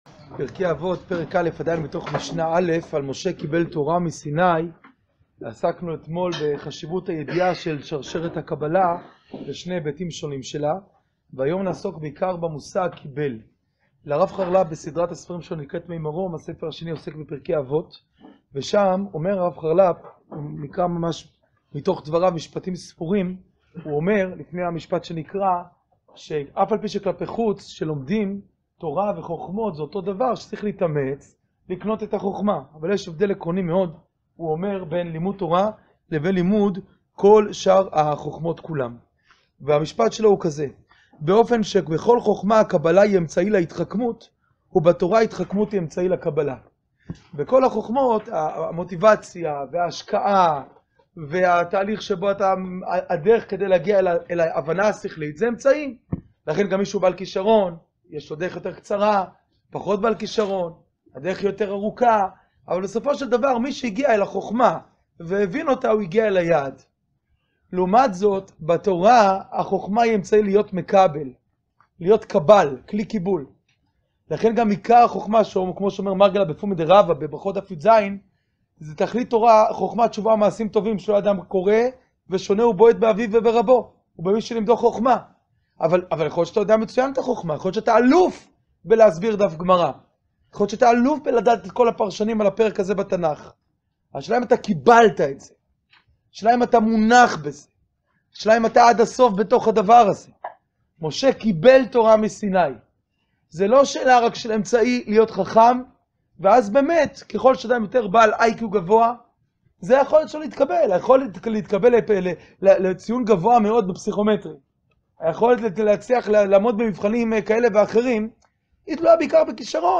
שיעור פרק א' משנה א'